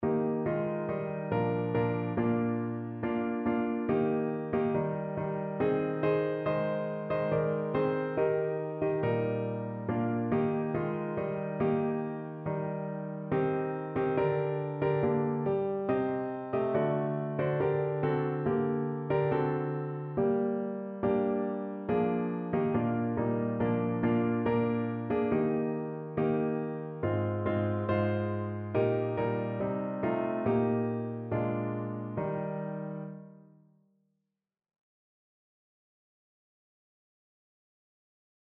Evangeliumslieder